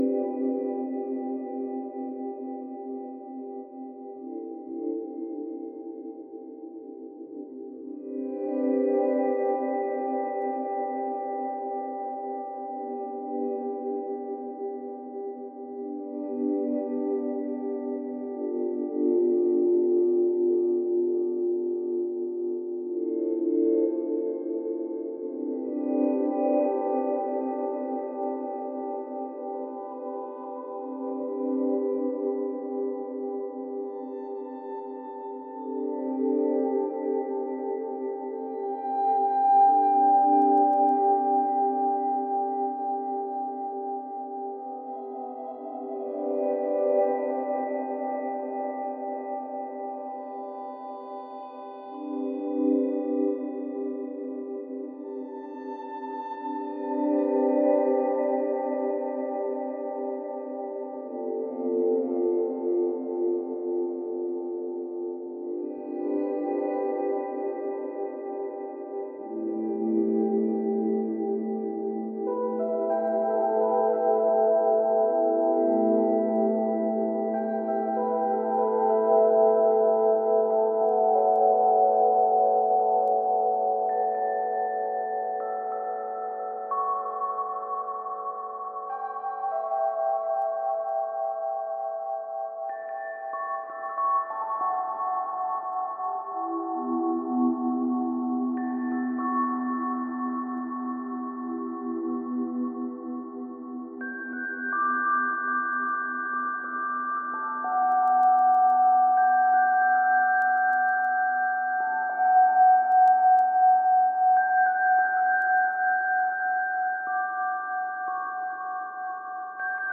experimental ambient